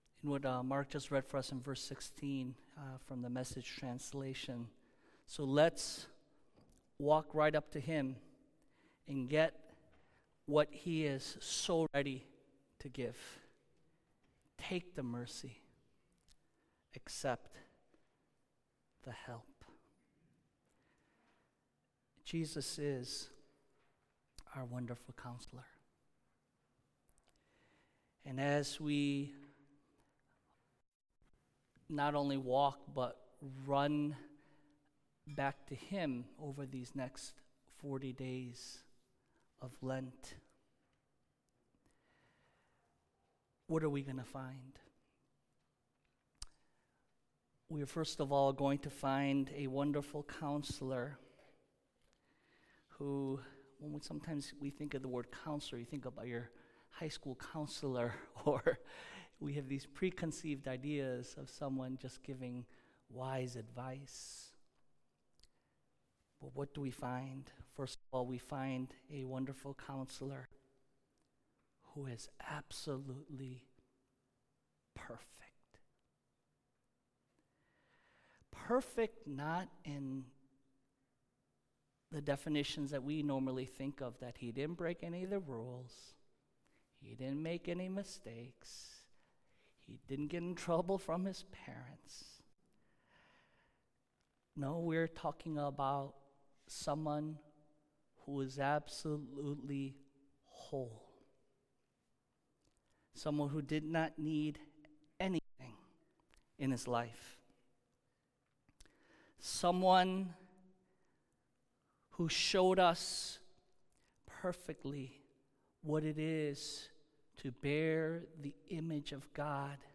Ash Wednesday Message 2022